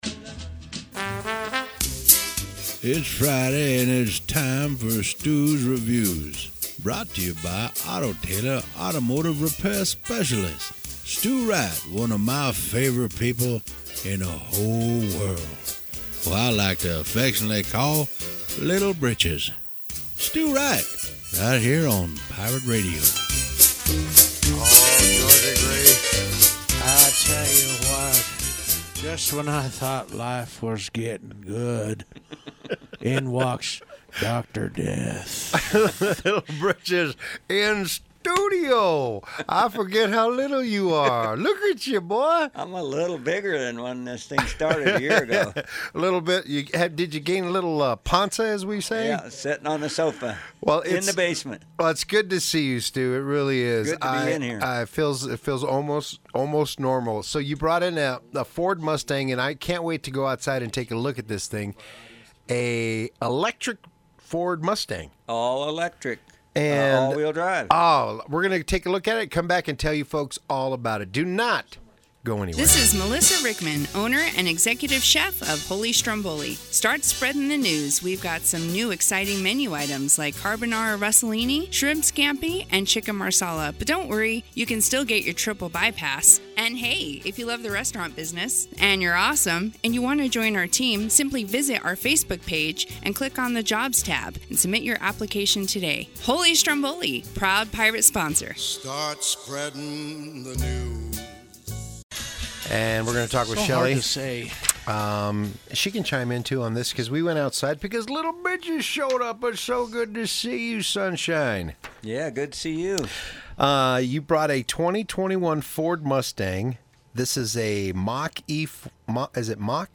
Radio Review